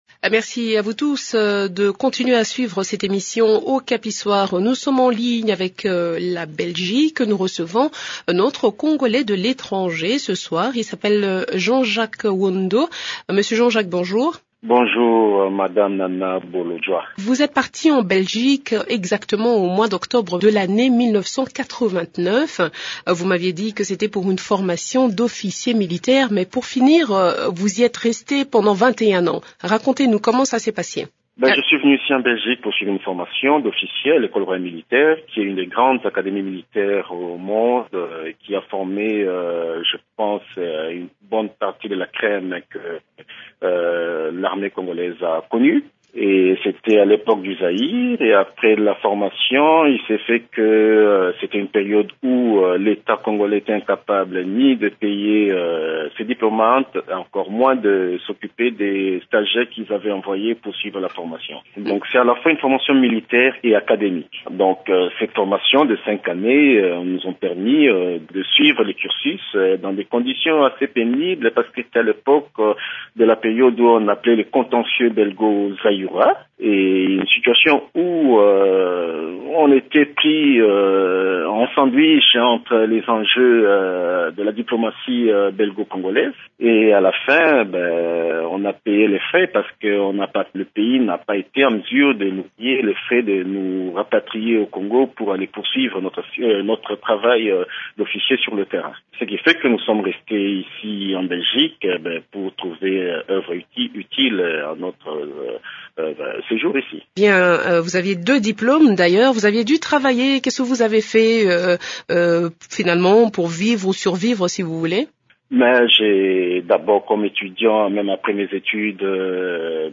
s’entretient ici avec